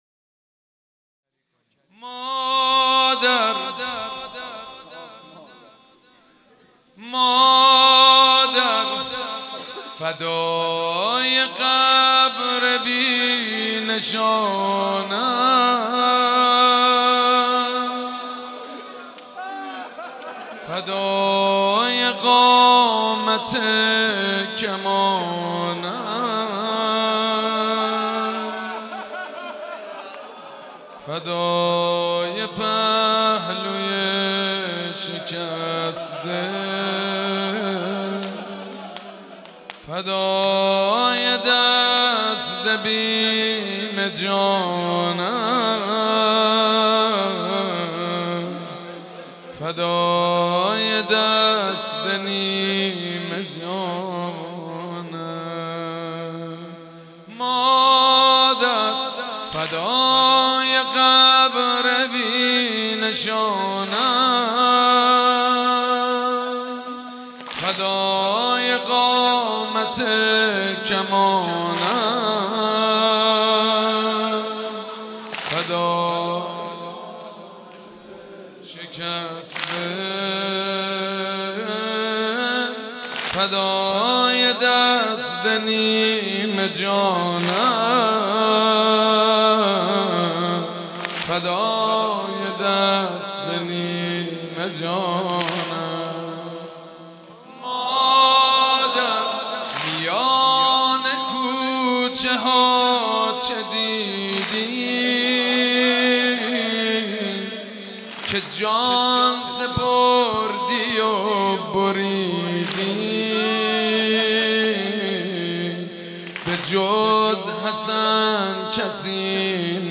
برچسب ها: مرثیه سرایی ، شهادت حضرت فاطمه (س) ، مداحی اهل بیت